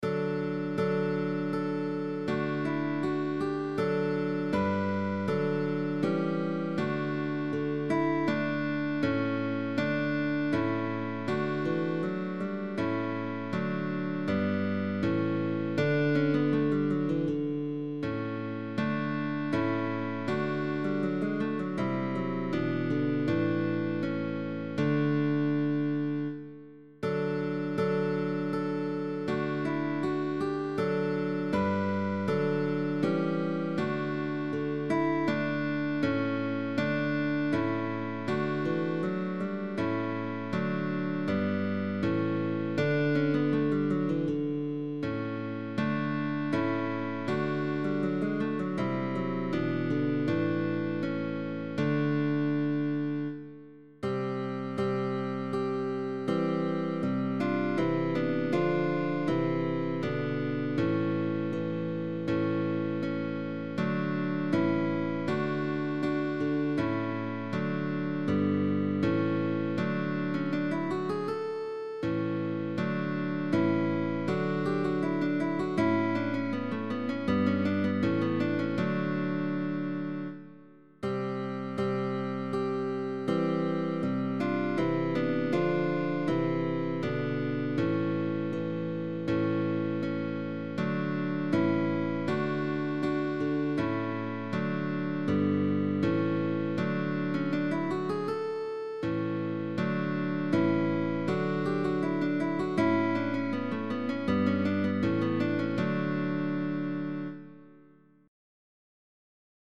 Early music